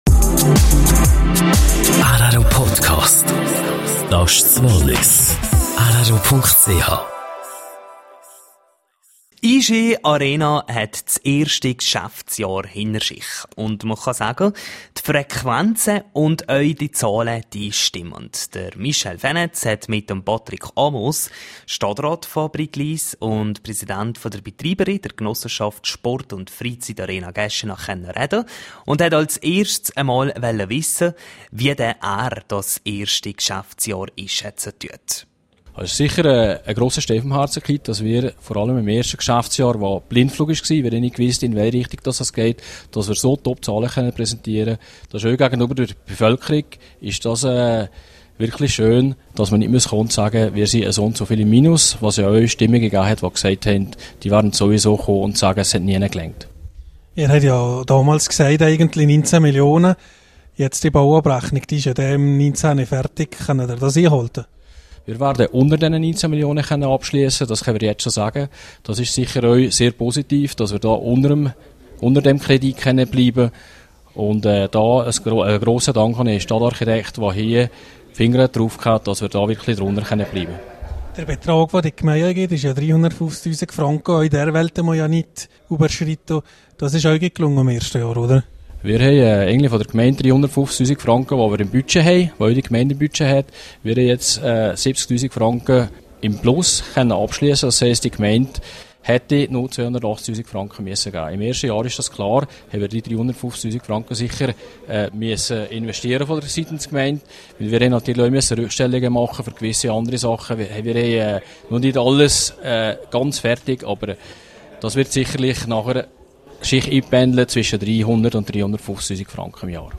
Ein Jahr "Iischi Arena": Interview mit Patrick Amoos, Stadtrat Brig-Glis.